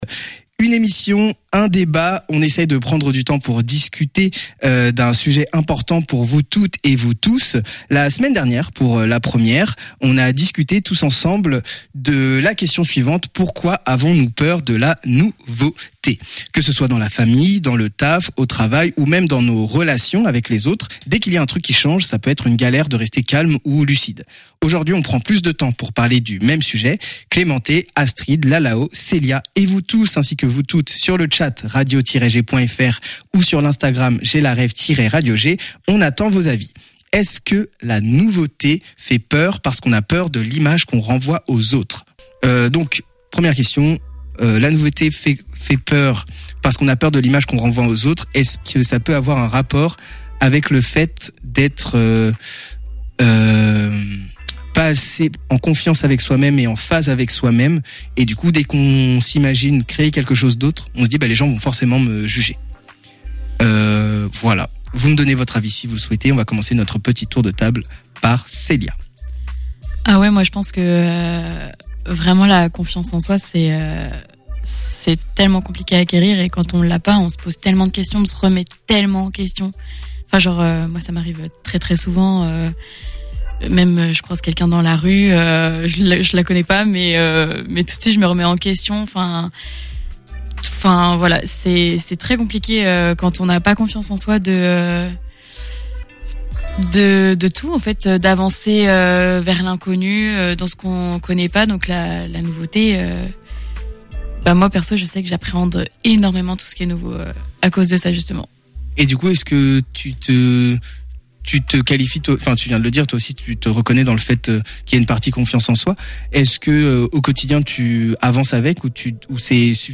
03_debat.mp3